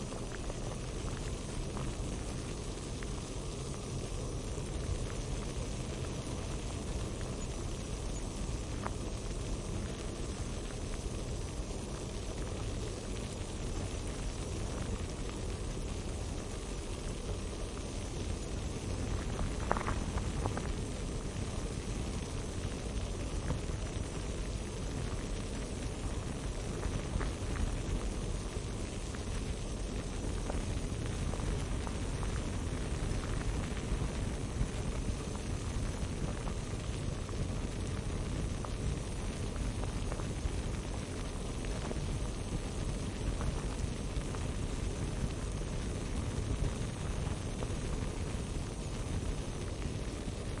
水泡沫咕噜声
描述：水泡“Blubs”用嘴创造的Blubs。用Bitwig录音。
Tag: 泡沫 呼噜